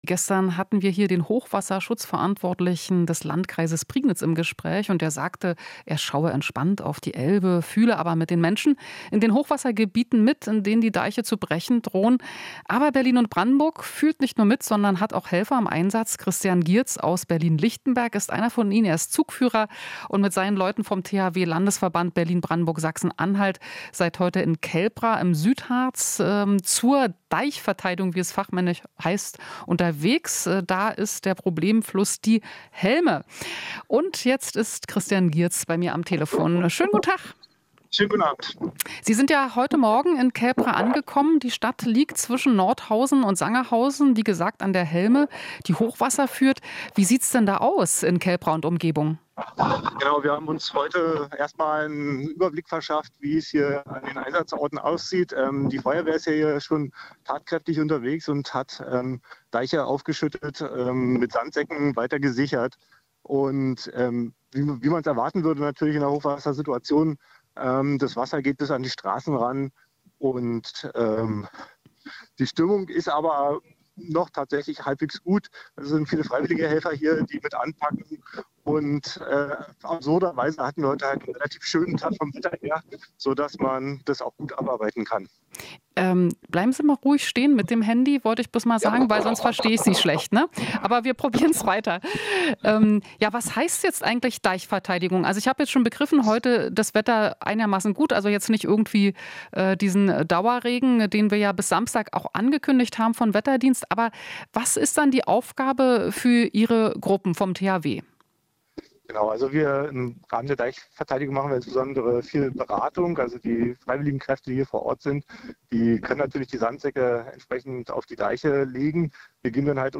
Interview - Berliner THW hilft im Hochwassergebiet im Südharz